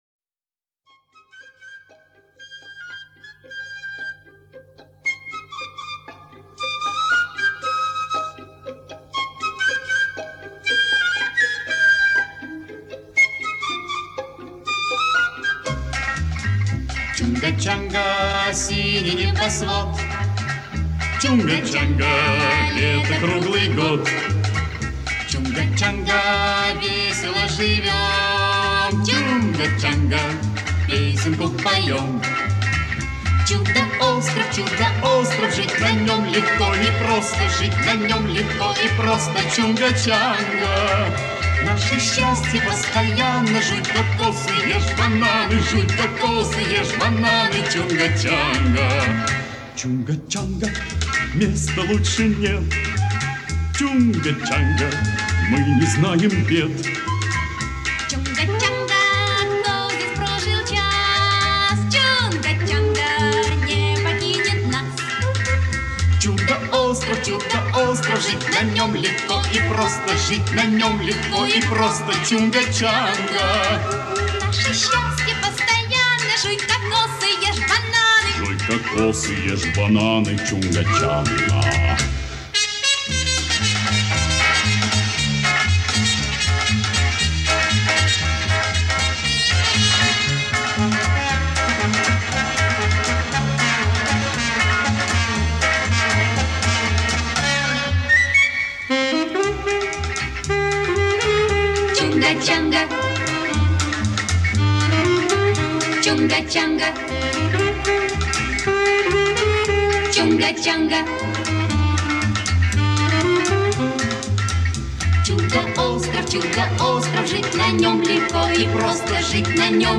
веселая и задорная песня